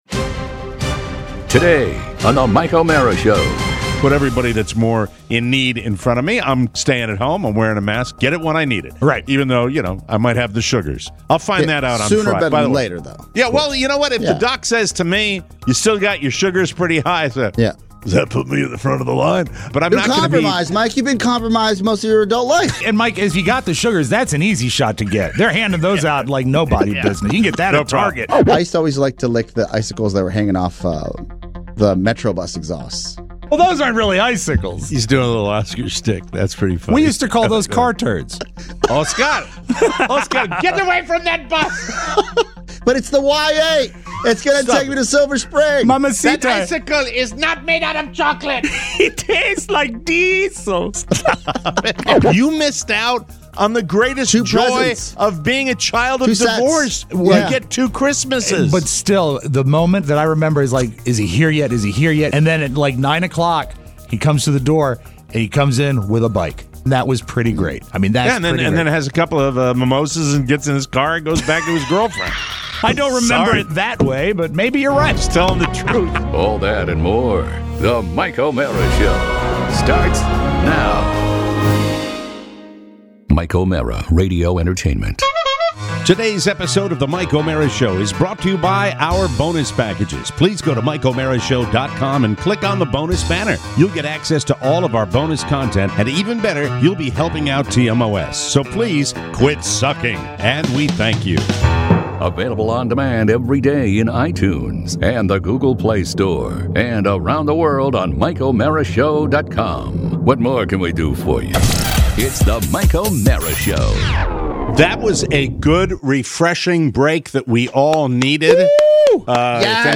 We're back in the studio!